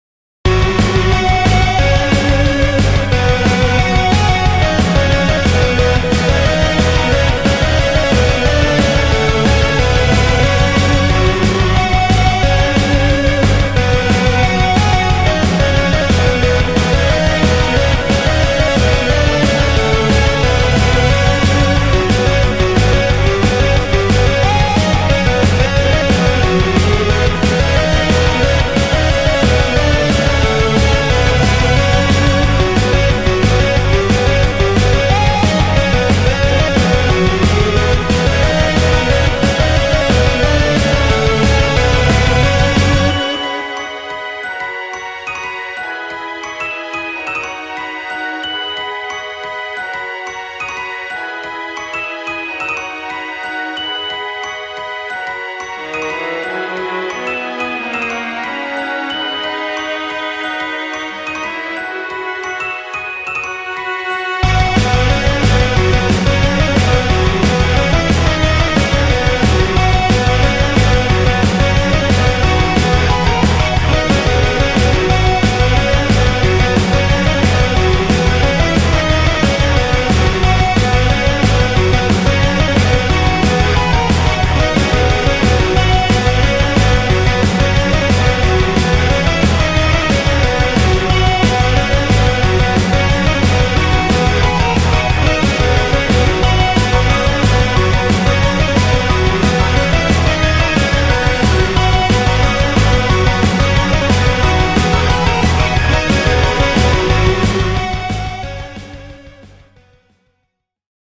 BPM180
Audio QualityPerfect (Low Quality)